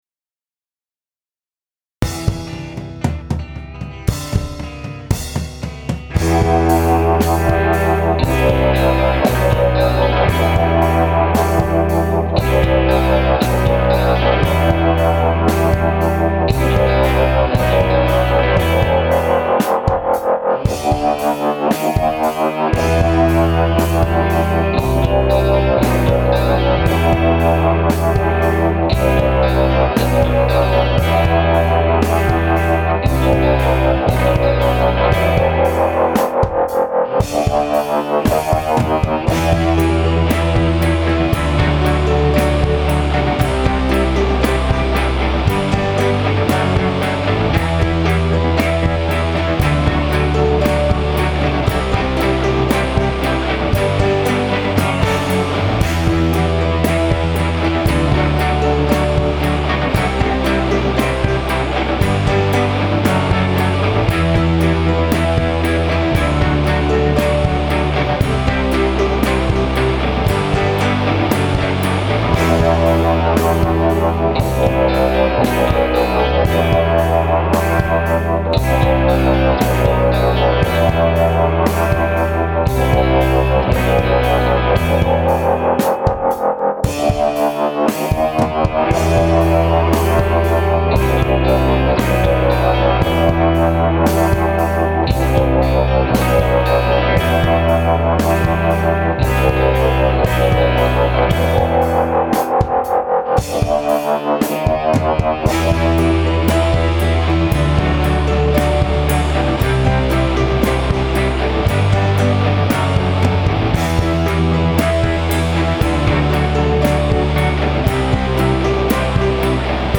The mastering was done more to get it up to volume/level things out but feel free to suggest!